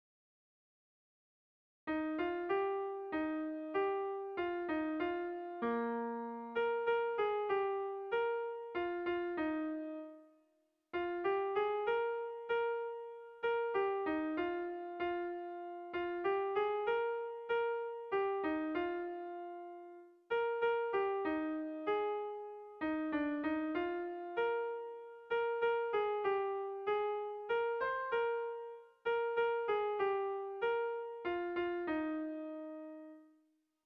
Luzaide < Zangozako Merindadea < Nafarroa < Euskal Herria
ABD